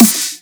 Levels_Snare.wav